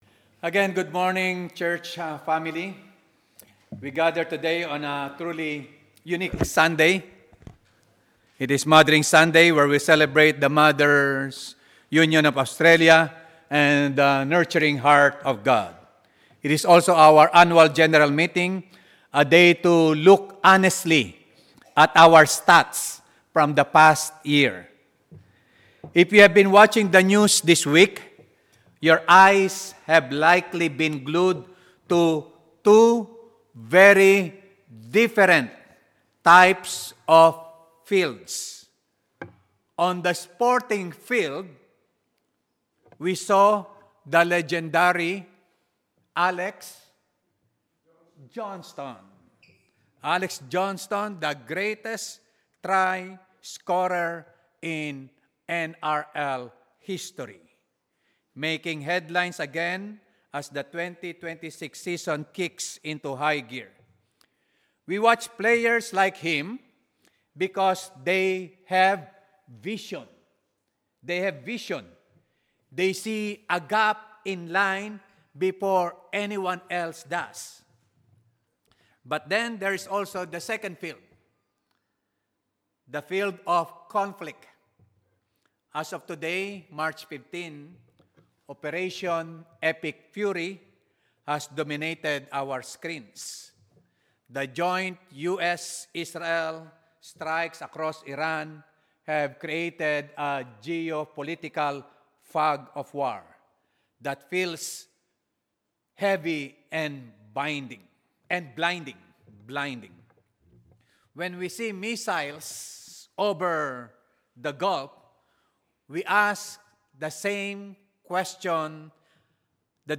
Sermons – Kotara Anglican
2026 Apr 26 – 4th Sunday of Easter